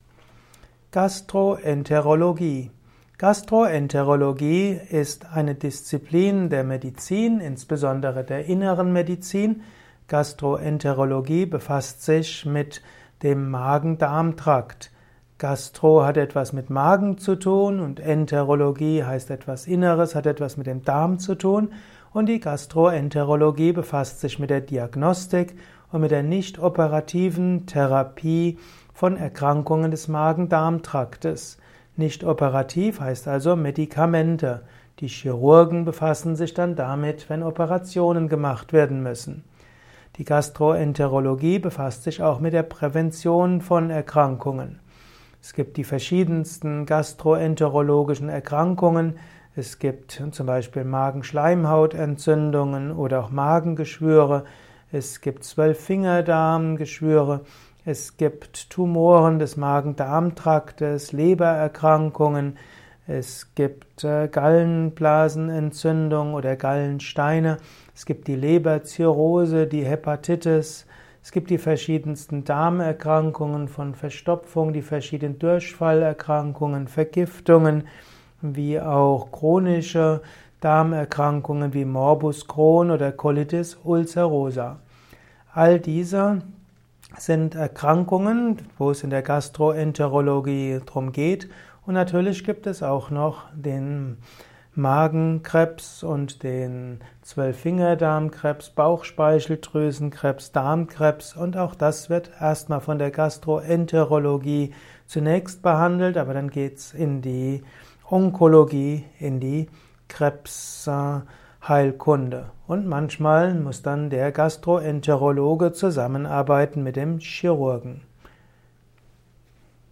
Ein Kurzvortrag über Gastroenterologie